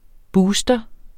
booster substantiv, fælleskøn Bøjning -en, -e, -ne Udtale [ ˈbuːsdʌ ] Oprindelse kendt fra 1986 jævnfør booste Betydninger 1.